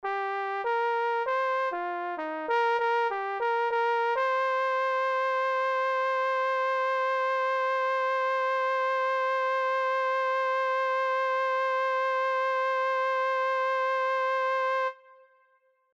Key written in: C Minor
Type: Barbershop
Each recording below is single part only.